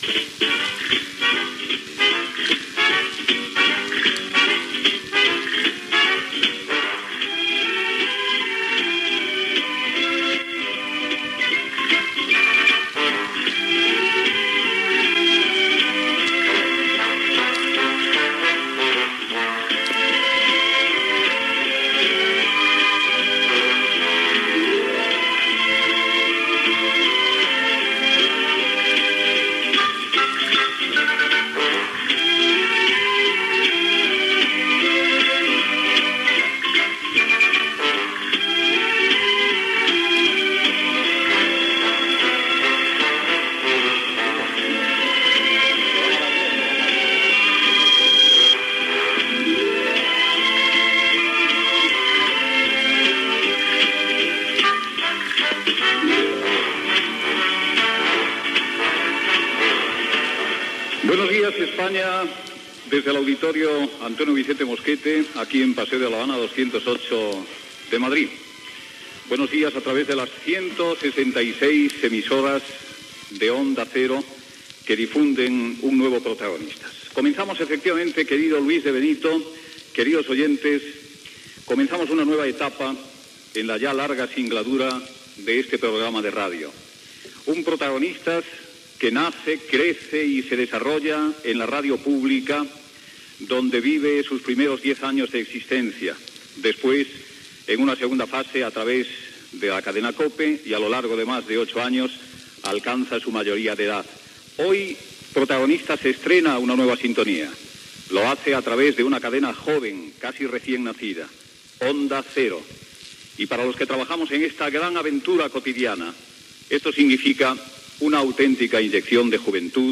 Sintonia, presentació del primer programa des d'Onda Cero, connexió amb la cadena de Radio Intercontinental de Madrid, salutació del rei Juan Carlos I, hora, publicitat
Info-entreteniment